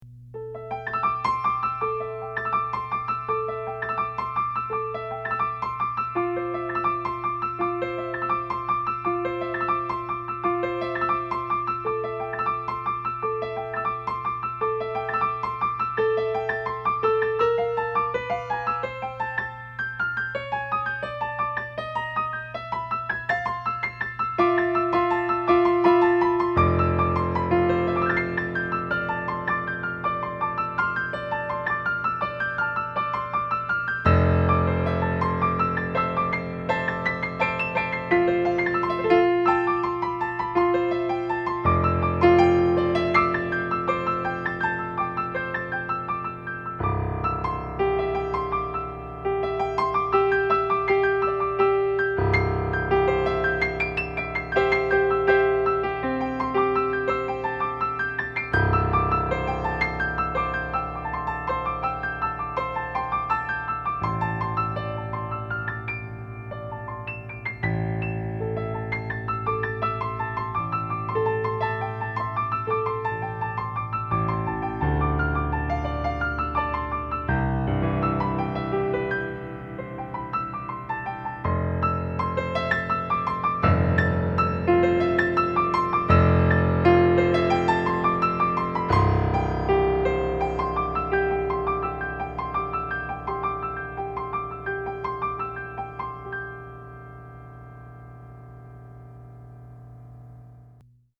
These improvisations are just musical ideas and expressions all created in the moment, a stream of consciousness. The keyboard used is a controller so the musical response quality is limited to the nature of the animal.